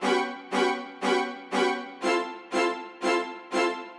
Index of /musicradar/gangster-sting-samples/120bpm Loops
GS_Viols_120-G.wav